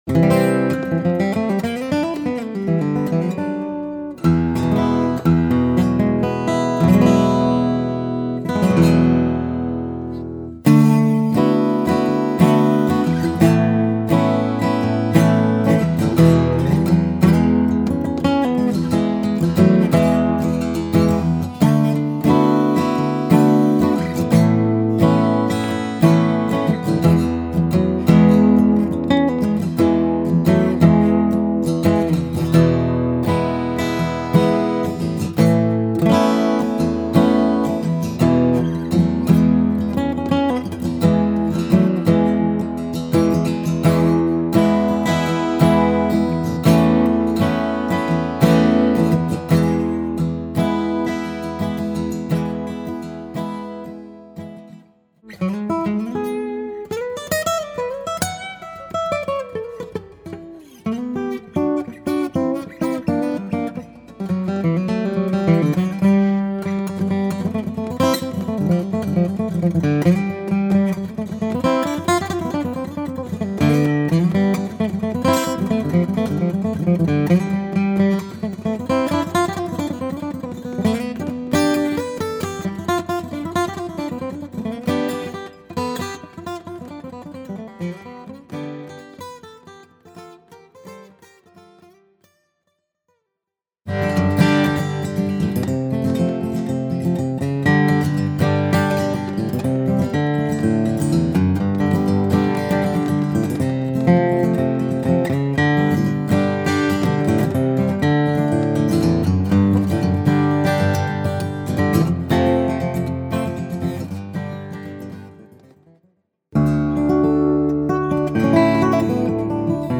The tone is spectacular and the guitar resonates for days.